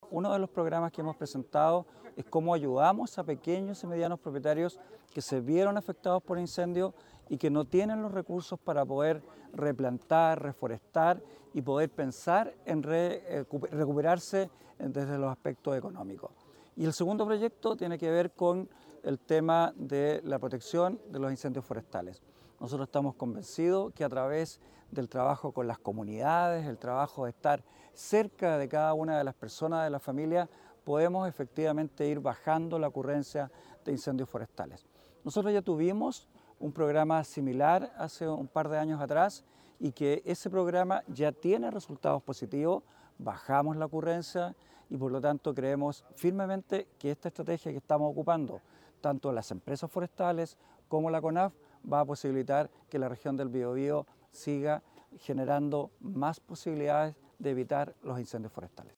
Además, considera un aporte biológico y ambiental, contribuyendo a la generación de bienes y servicios de comunidades rurales y a la mitigación del cambio climático, lo que fue valorado por el director regional de CONAF, Esteban Krause.